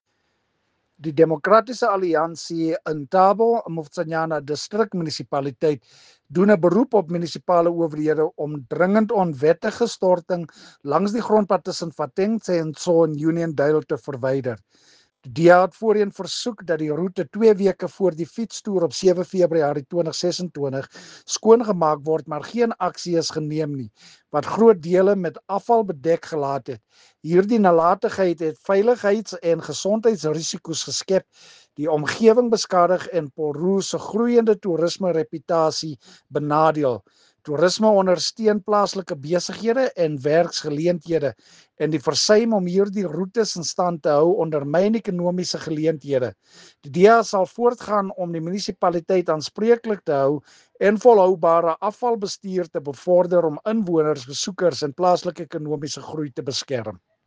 Afrikaans soundbites by Cllr Marius Marais and